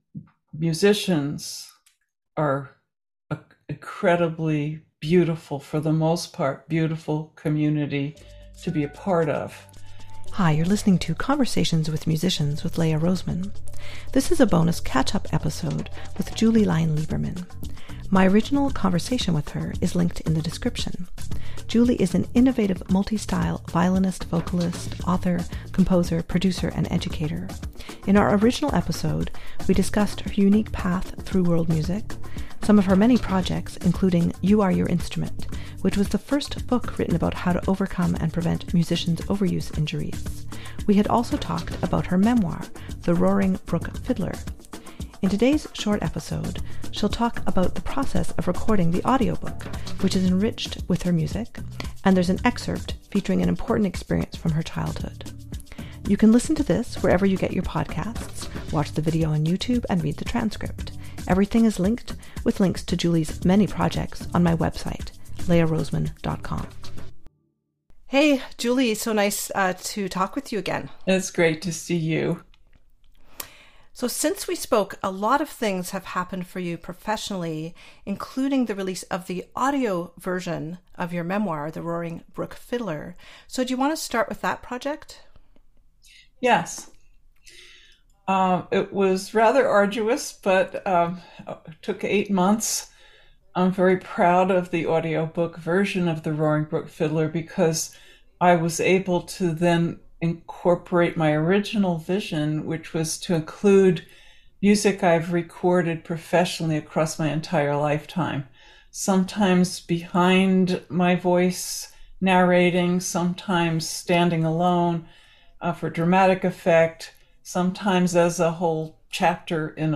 In today’s short episode, she’ll talk about the process of recording the audio-book, which is enriched with her music, and there’s an excerpt featuring an important experience from her childhood.